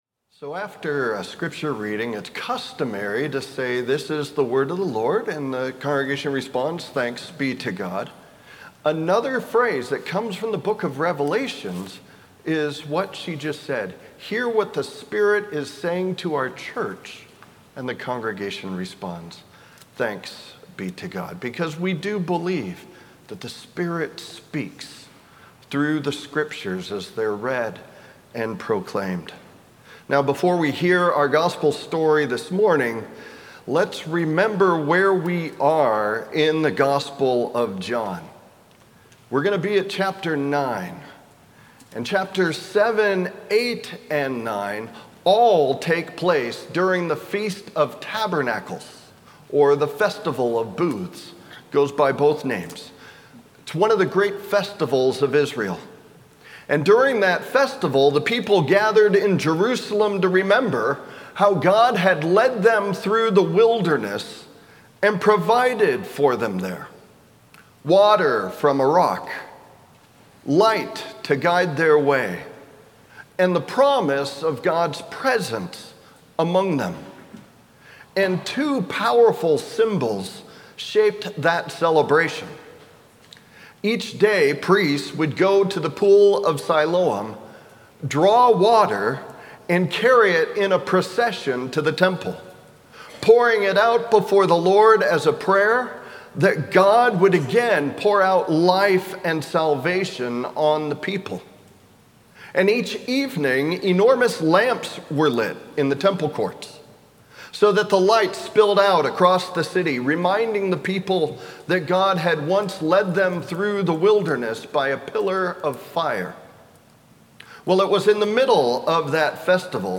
Sermon+3-15-26.mp3